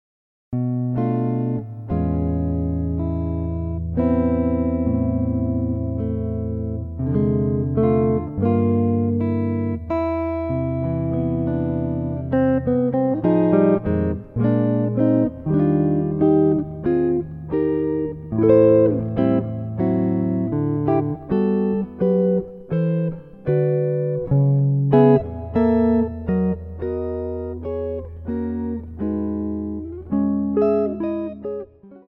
solo electric guitar